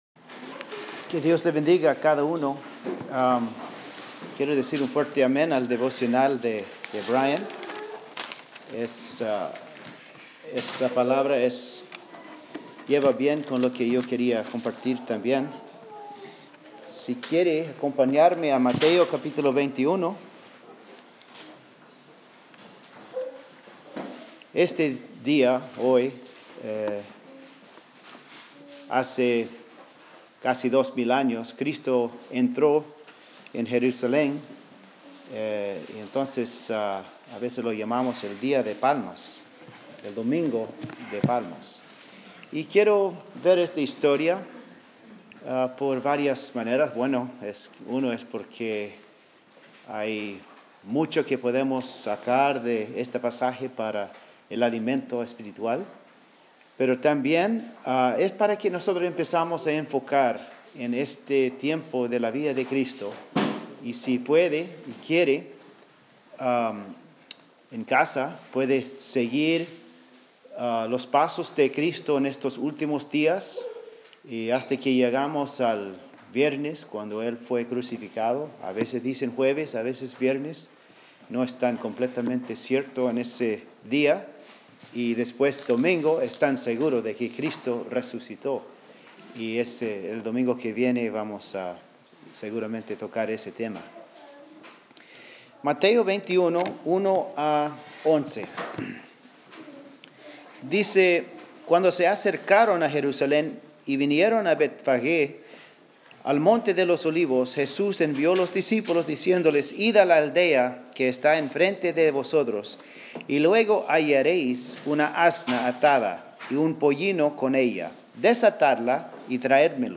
Este Sermon es acerca de como Dios ocupa instrumentos humildes como la asna para su gloria. 1 Corintios 1 dice: lo necio del mundo escogió Dios, para avergonzar a los sabios; y lo débil del mundo escogió Dios, para avergonzar a lo fuerte; y lo vil del mundo y lo menospreciado escogió Dios.